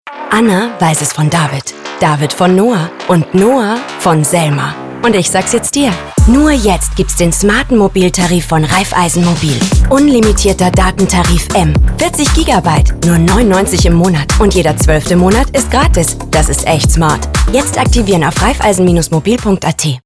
Radio Spot: Raiffeisen Mobil